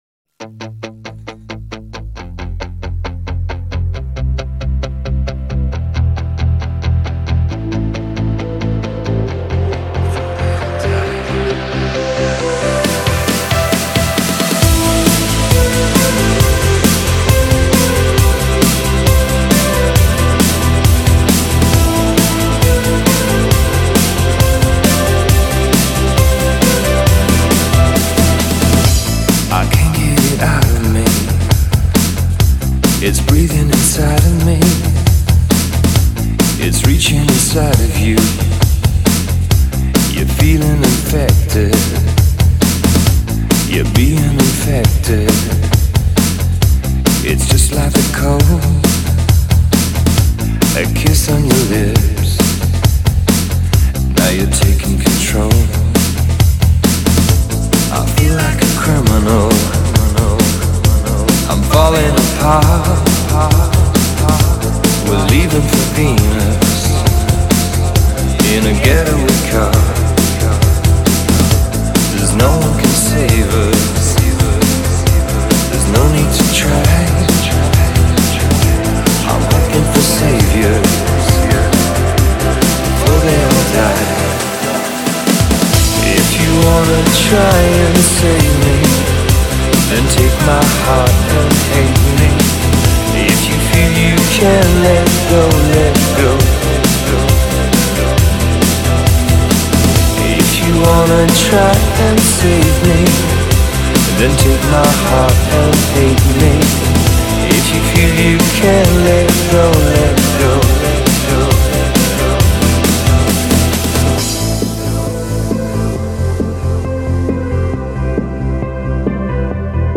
Подстиль: Vocal Trance